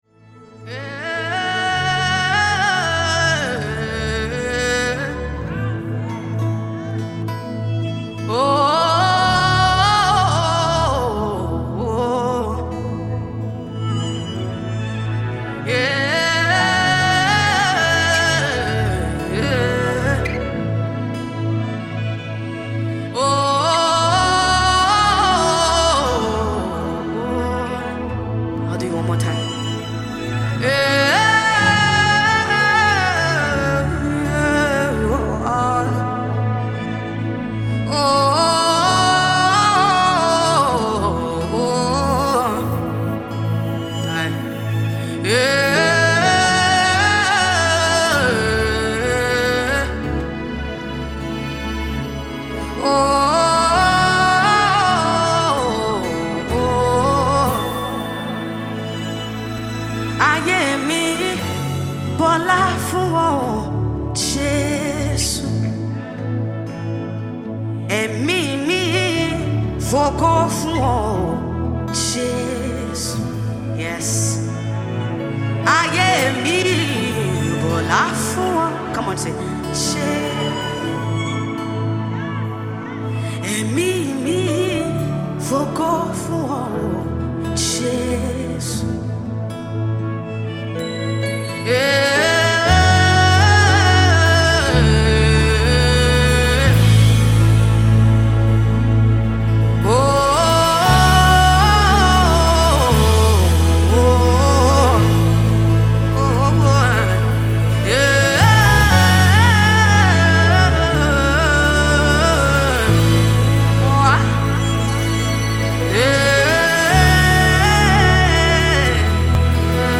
gospel
remix
recorded live
stirring vocals